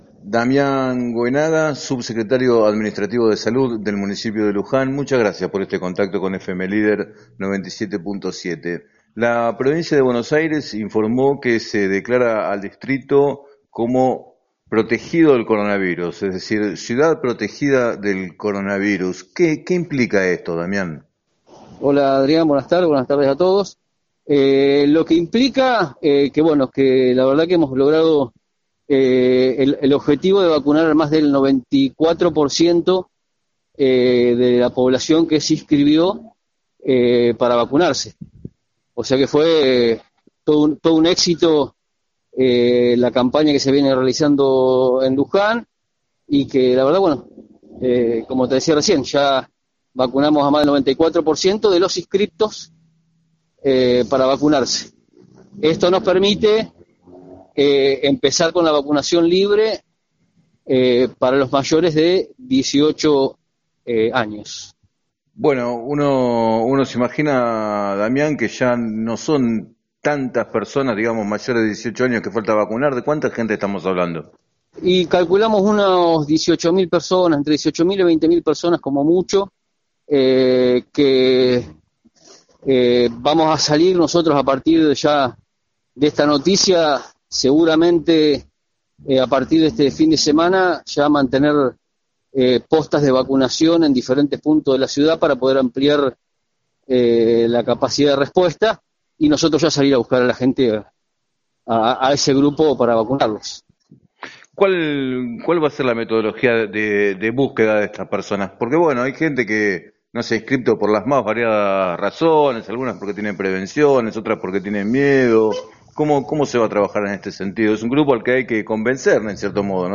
En declaraciones al programa “7 a 9” de FM Líder 97.7, el subsecretario Administrativo de Salud, Damián Goenaga, explicó que iniciarán una búsqueda activa de quienes no se hayan vacunado para persuadirlos de que lo hagan.